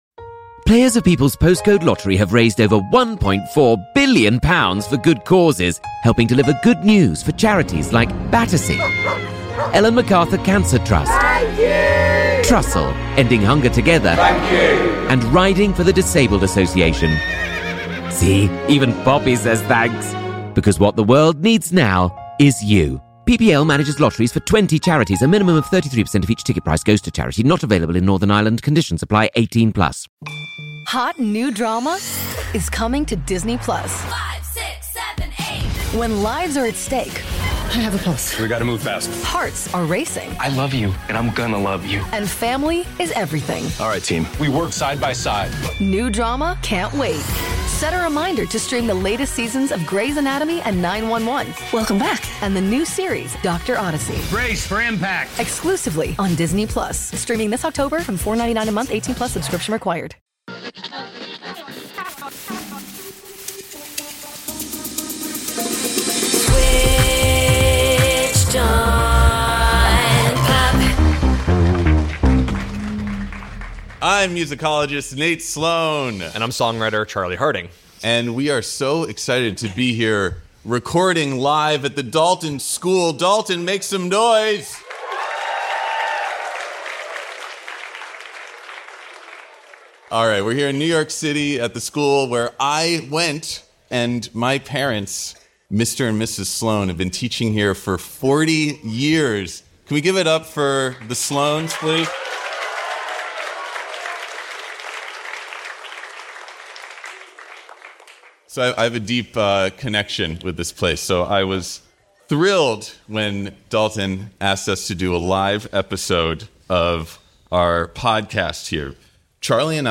Is there a way to make music scary? In a live show from The Dalton School in New York City